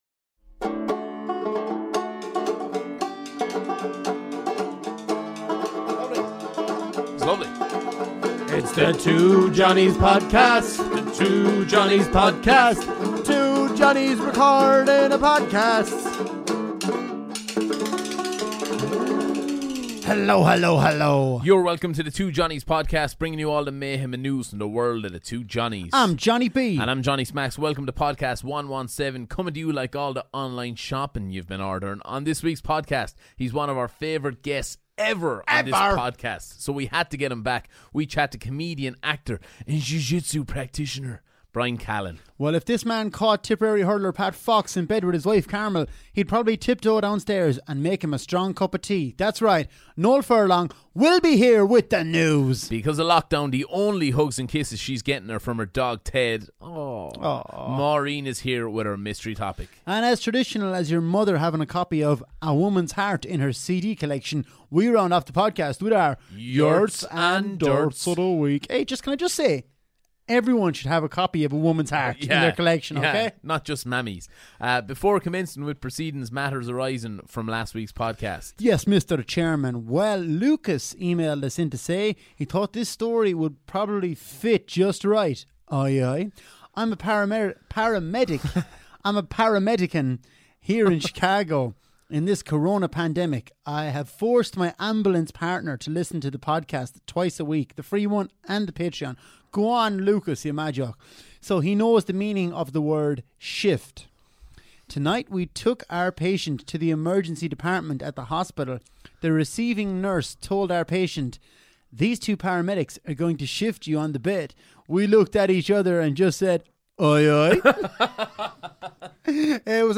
Ireland's favourite comedy duo tackle the big issues, This week: